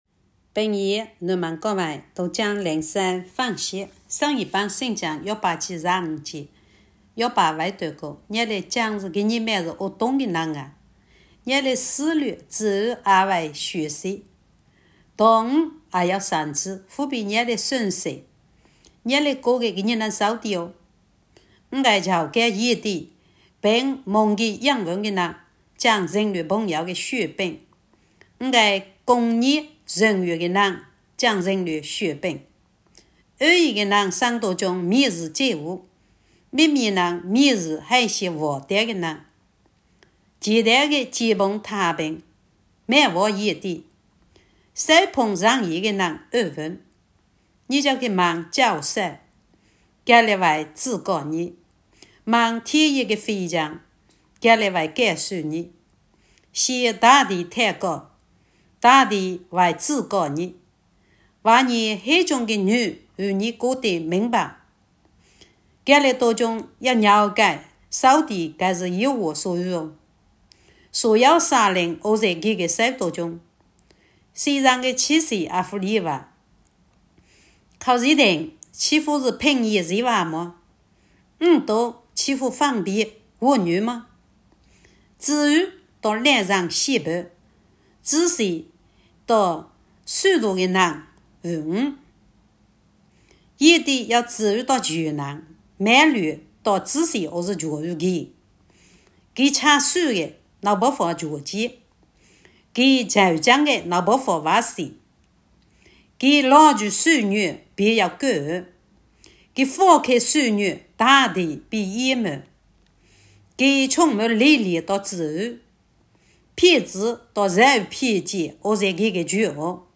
平阳话朗读——伯12